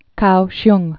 (koushyng, gou-)